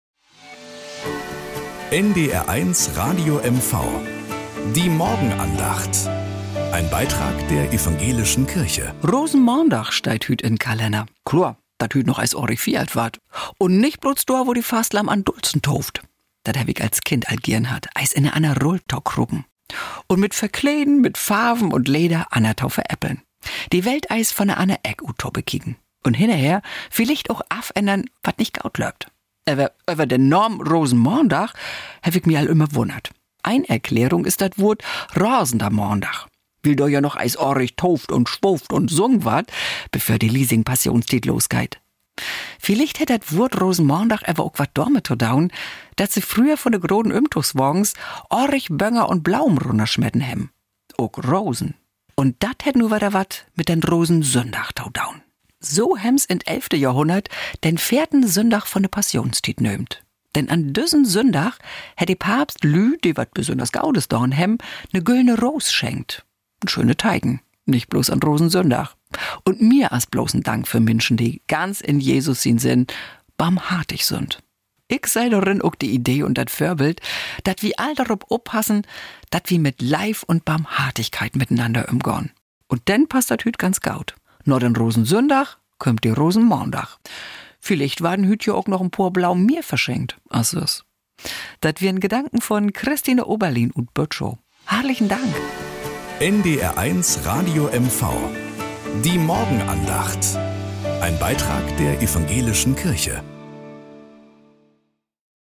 Nachrichten aus Mecklenburg-Vorpommern - 25.04.2025